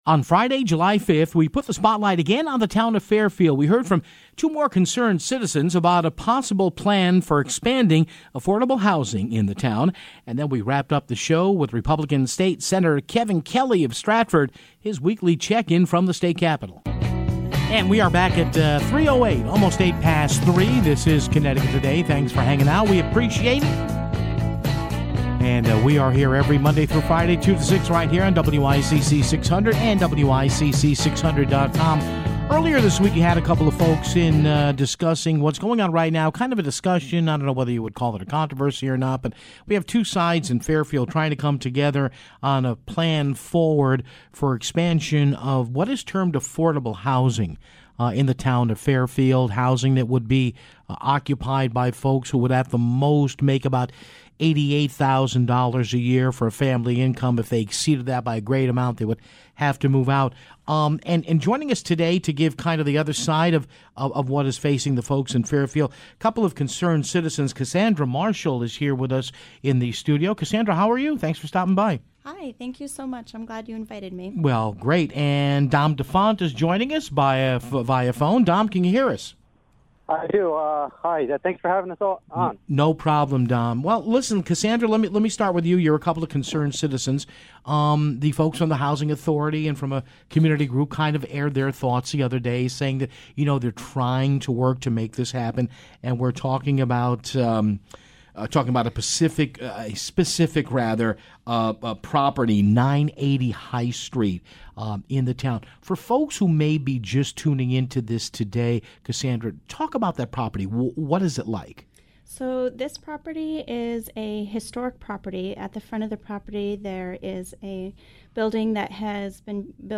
Then, State Senator Kevin Kelly joins the program for his weekly spot on the latest with the General Assembly.